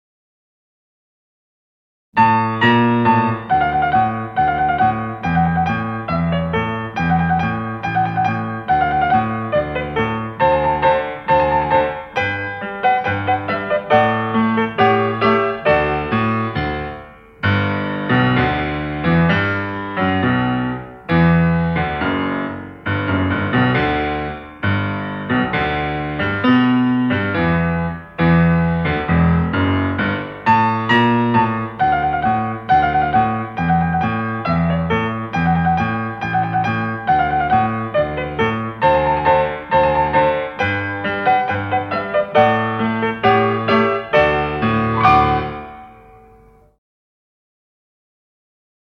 20 Klavierstücke für Kinder
Klavier